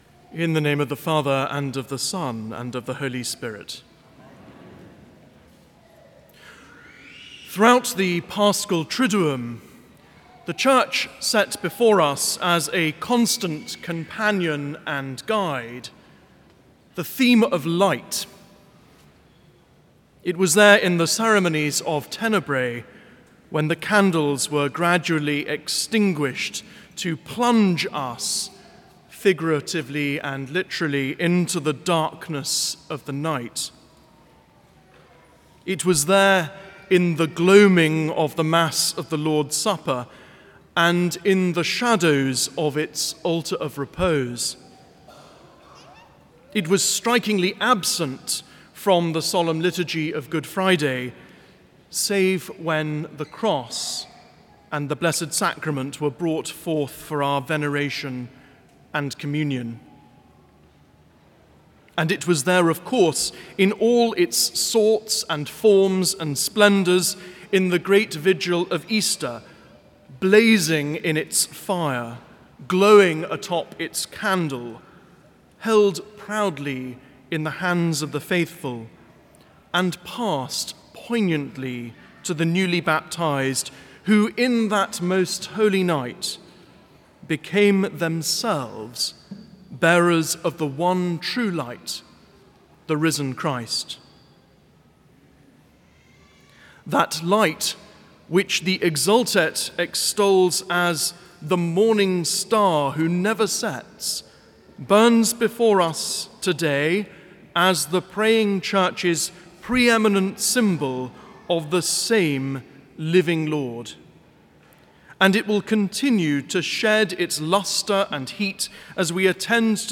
Homilies - Prince of Peace Catholic Church & School